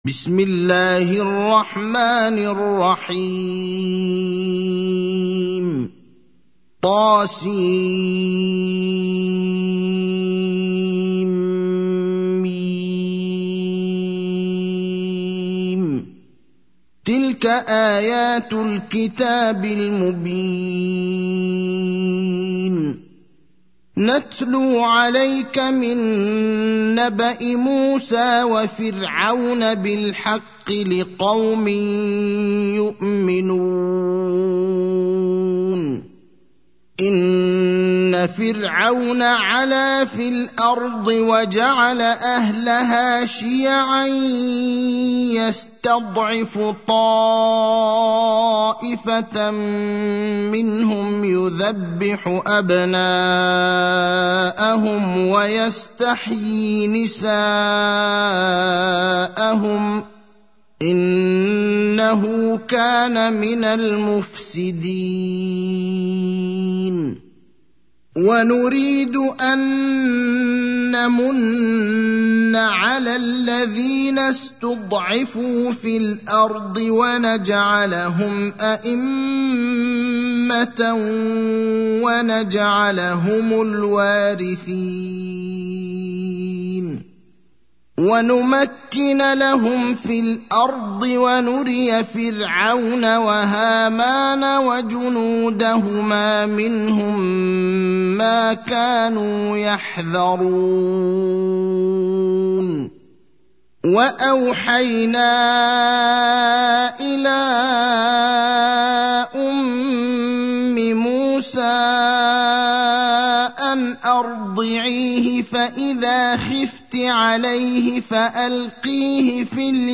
المكان: المسجد النبوي الشيخ: فضيلة الشيخ إبراهيم الأخضر فضيلة الشيخ إبراهيم الأخضر القصص (28)